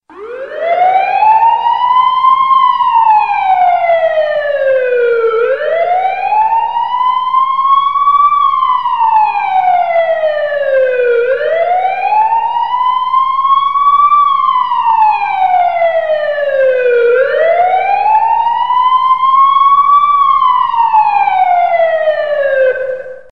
3D-Siren
3D-Siren.mp3